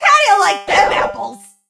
jackie_drill_kill_vo_04.ogg